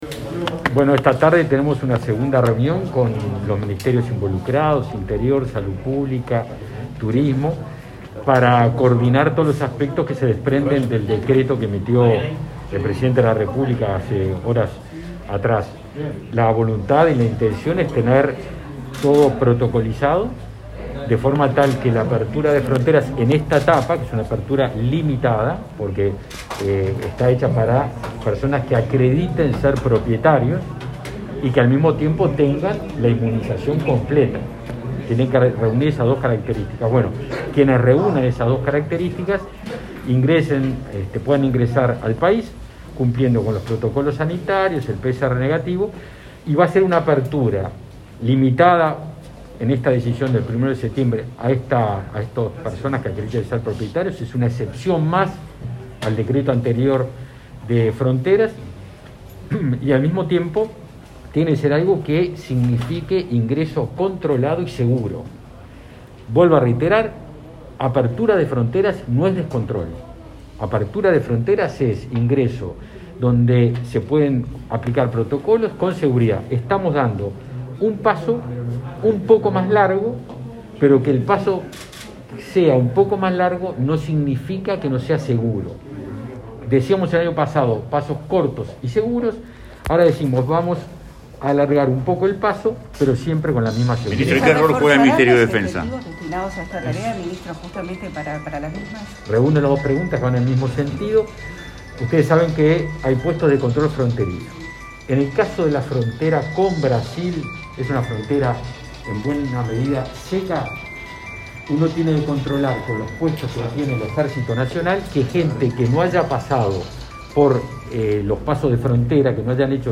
Declaraciones de prensa del ministro de Defensa Nacional, Javier García
Declaraciones de prensa del ministro de Defensa Nacional, Javier García 30/08/2021 Compartir Facebook X Copiar enlace WhatsApp LinkedIn Este 30 de agosto, el Correo Uruguayo presentó un sello conmemorativo, alusivo a los 30 años de la de la primera misión naval antártica a bordo del buque Pedro Campbell y como parte de la celebración del 46.° aniversario del Instituto Antártico Uruguayo. Tras la ceremonia, el ministro García efectuó declaraciones a la prensa.